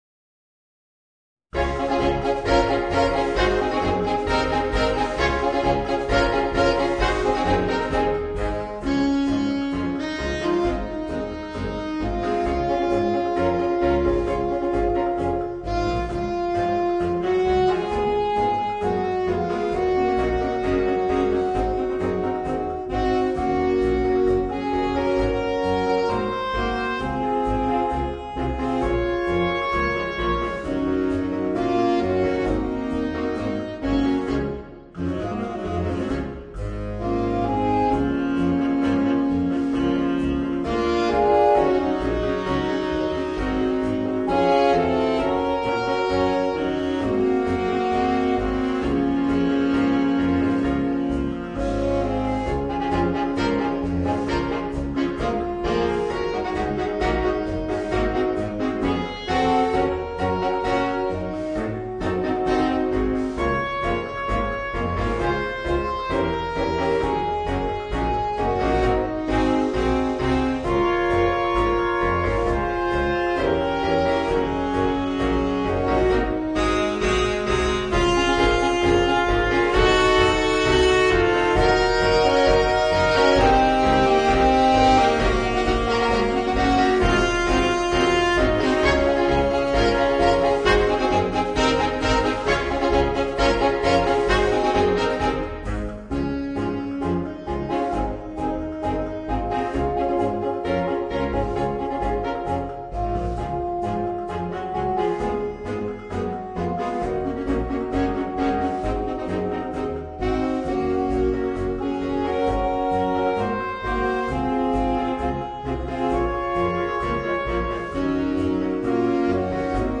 Voicing: 4 Saxophones